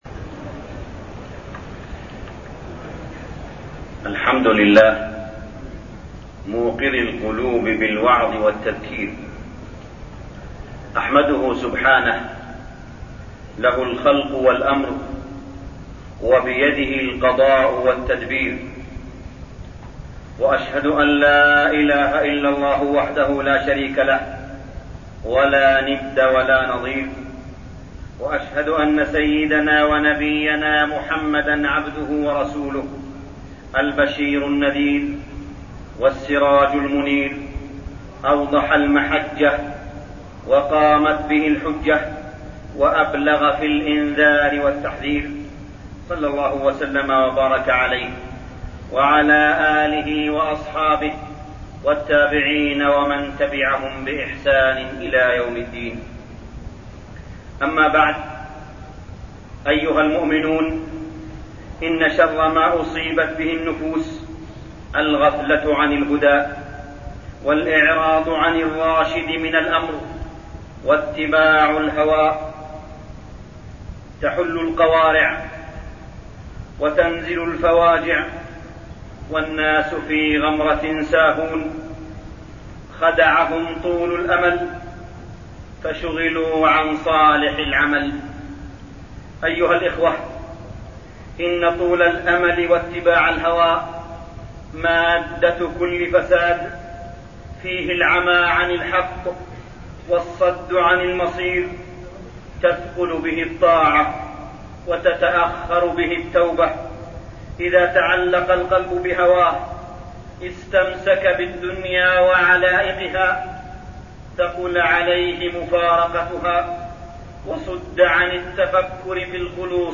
تاريخ النشر ١٧ شعبان ١٤٠٩ هـ المكان: المسجد الحرام الشيخ: معالي الشيخ أ.د. صالح بن عبدالله بن حميد معالي الشيخ أ.د. صالح بن عبدالله بن حميد طول الأمل و إتباع الهوى